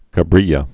(kə-brēyə, -brĭlə)